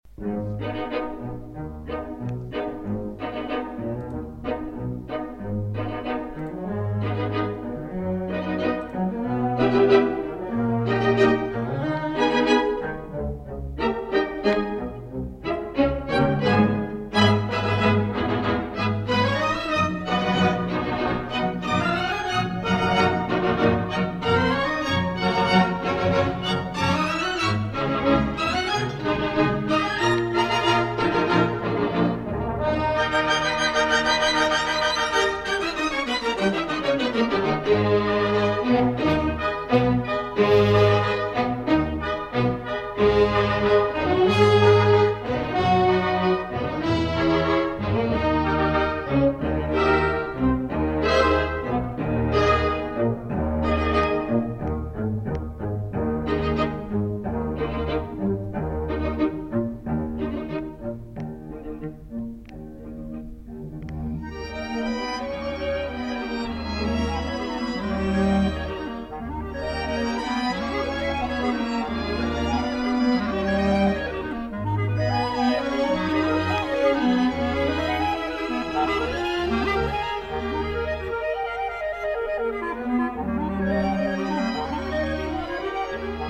Прошу прощения за качество звука.
rahmaninov---prelyudiya-5-23.-starshiy-syin.mp3